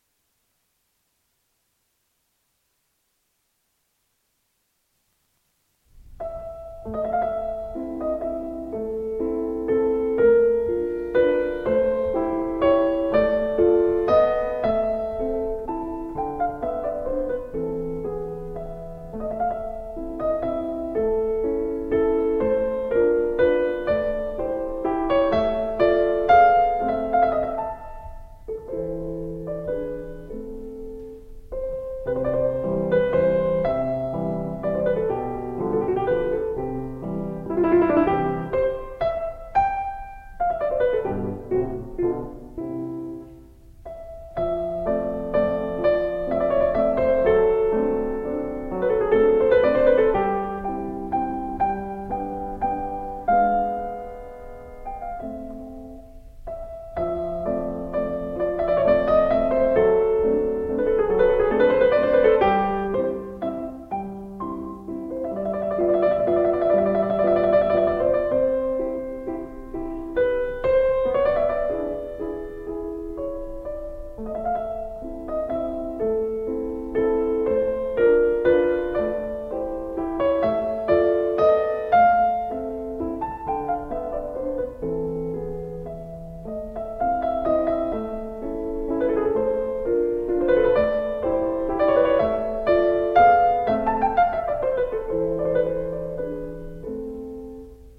DESCRIPTION OF THE PIECE: a lovely single-movement piano piece in rondo form.
It has a lovely lilting melody with more than a hint of melancholy.
Rondo first A